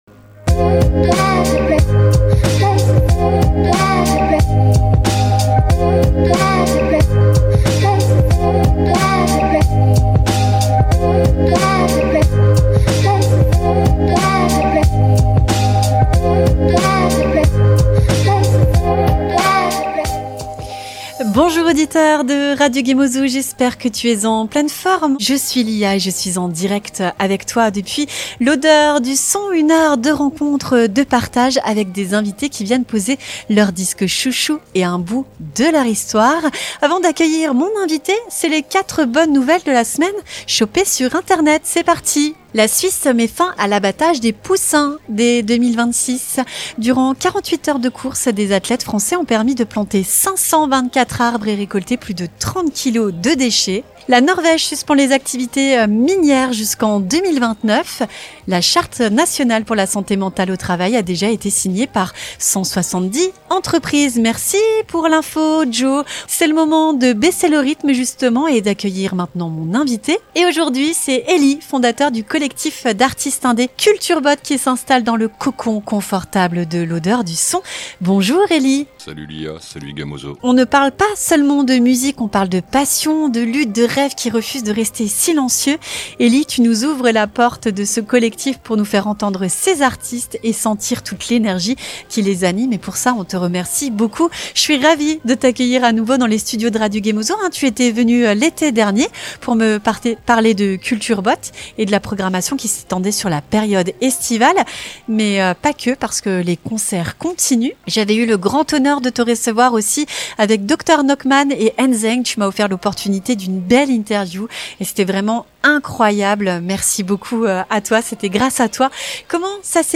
Jazz‑électro, hip‑hop, électro downtempo : chaque morceau de cette playlist est une invitation à sentir l’énergie qui anime ce collectif et l’engagement de ceux qui font vivre la musique indépendante.